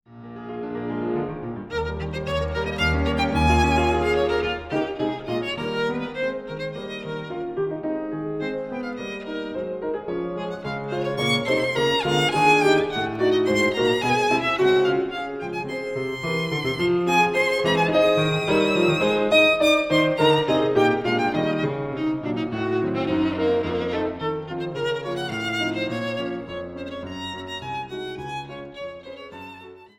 Violine
Lento